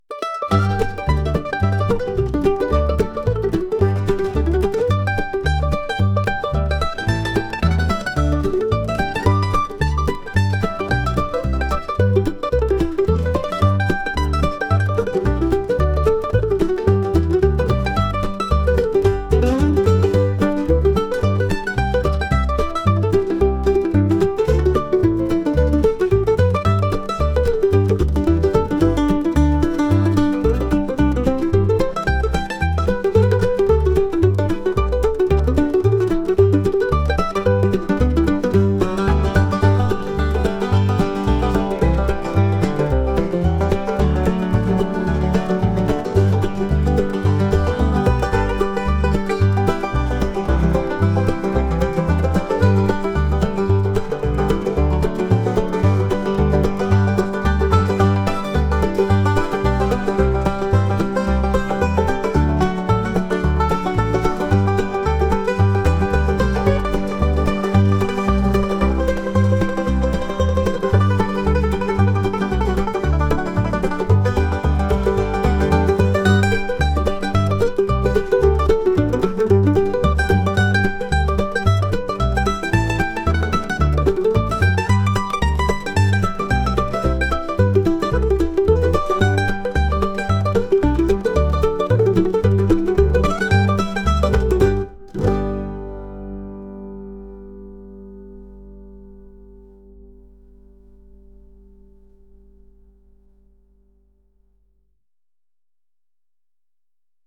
ウエスタン風味のギター曲です 音楽素材（MP3）ファイルのダウンロード、ご利用の前に必ず下記項目をご確認ください。